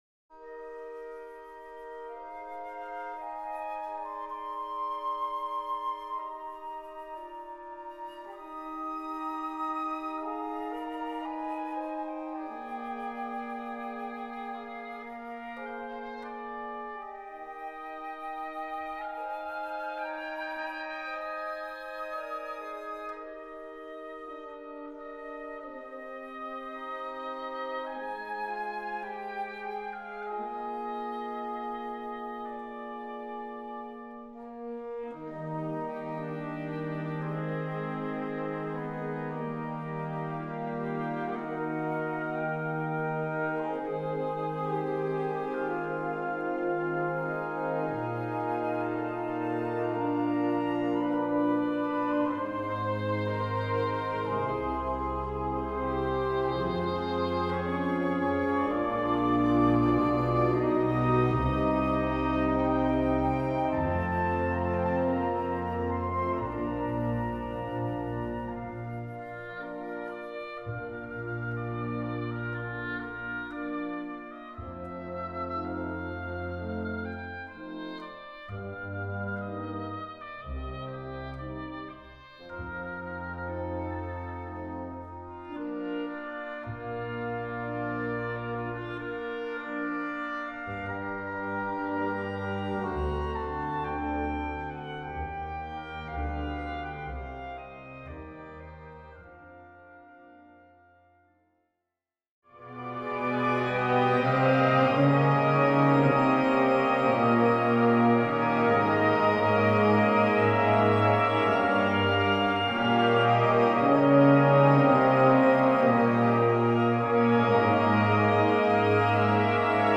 Feierliche Musik
Besetzung: Blasorchester